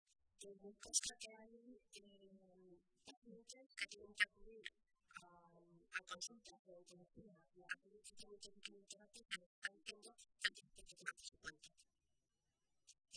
Guadalupe Martín, diputada Nacional del PSOE de Castilla-La Mancha
Cortes de audio de la rueda de prensa